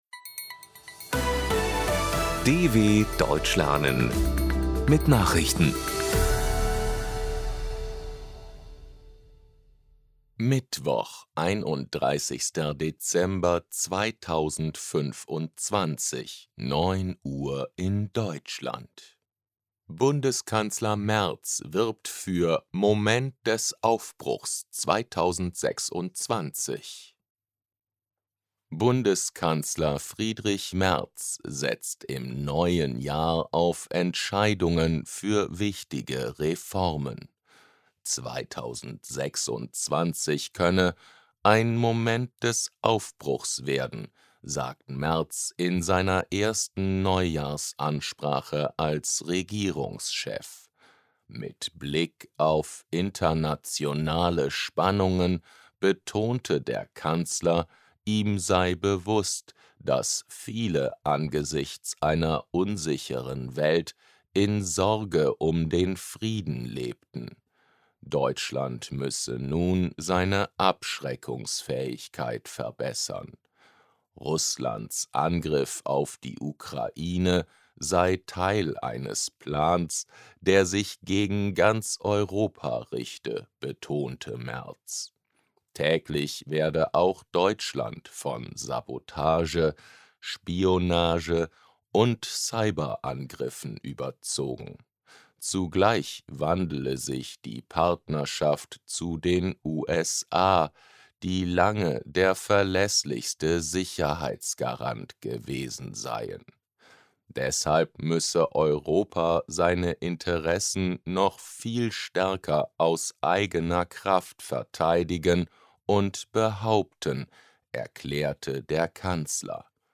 31.12.2025 – Langsam Gesprochene Nachrichten
Trainiere dein Hörverstehen mit den Nachrichten der DW von Mittwoch – als Text und als verständlich gesprochene Audio-Datei.